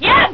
ChunLi-throw.wav